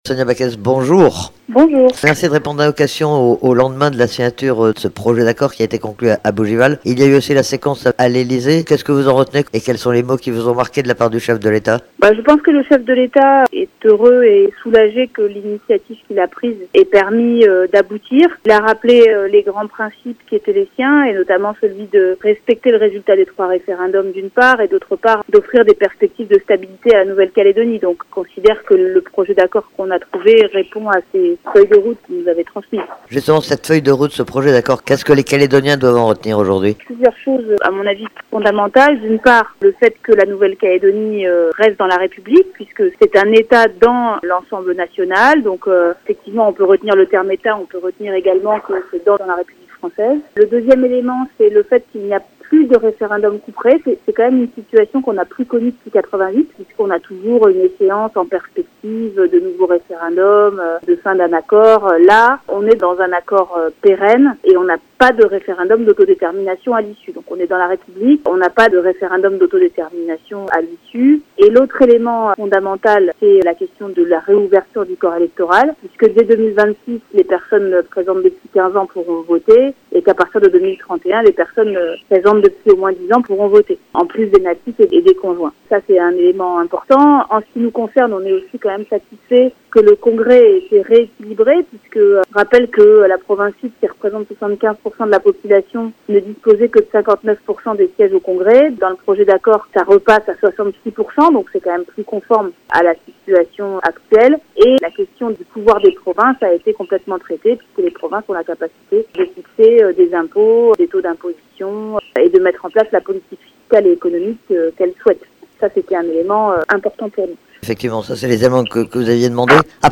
Une interview de Sonia Backès sur RRB